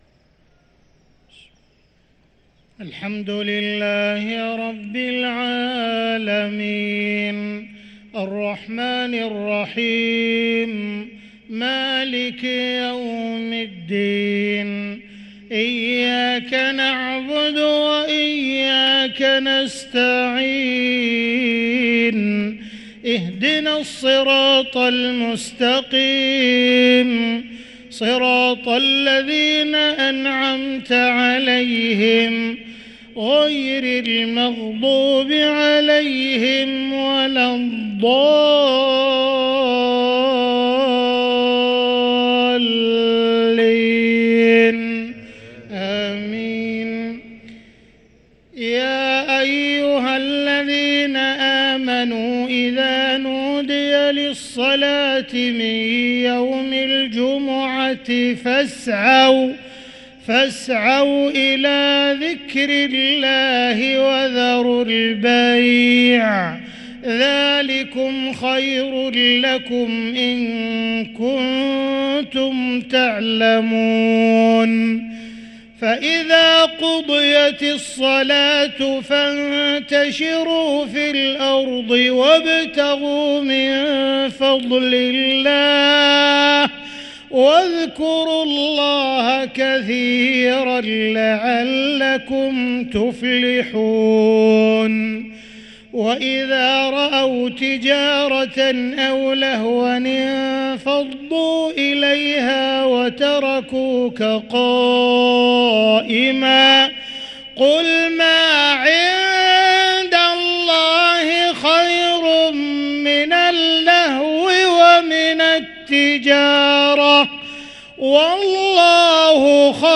صلاة المغرب للقارئ عبدالرحمن السديس 22 رمضان 1444 هـ